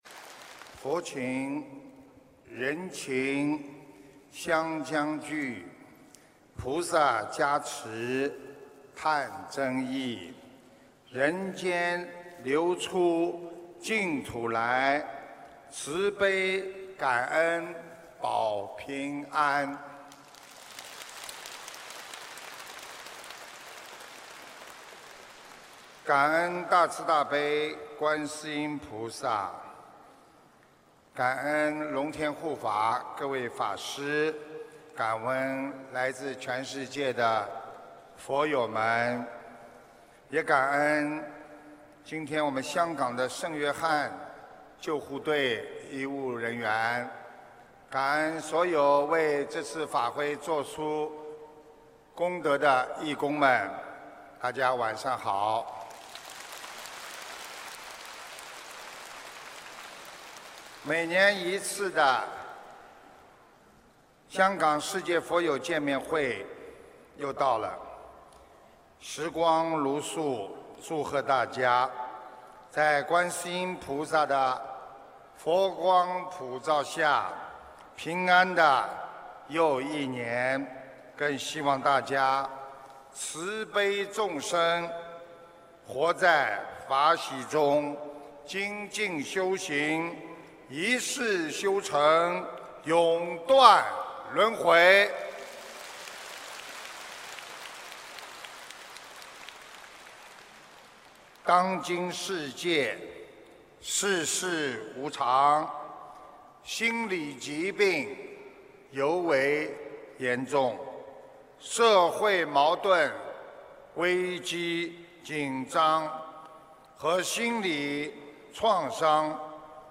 2016年7月2日香港